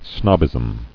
[snob·bism]